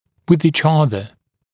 [wɪð iːʧ ‘ʌðə][уиз и:ч ‘азэ]друг с другом